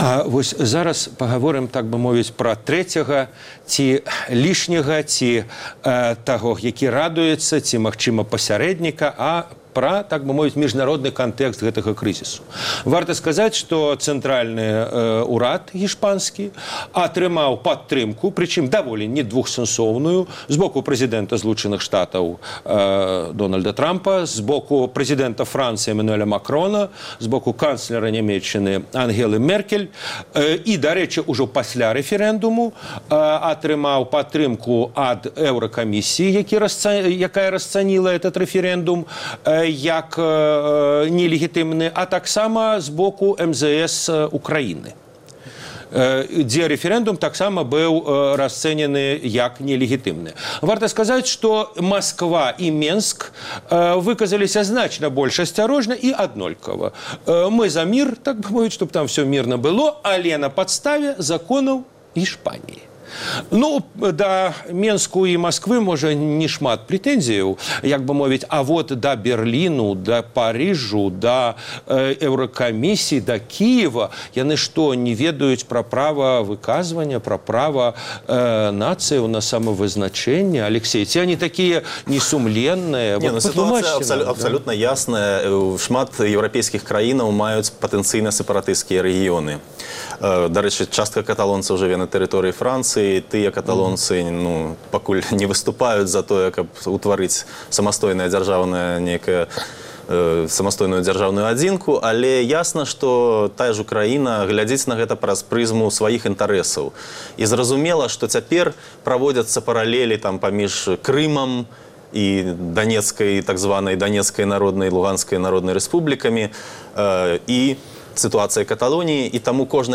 Зона Свабоды - штотыднёвая аналітычная перадача на тэлеканале Белсат Тэма выпуску – рэфэрэндум аб незалежнасьці Каталёніі. Што важней: права на самавызначэньне ці тэрытарыяльная цэласнасьць?